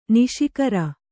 ♪ niśi kara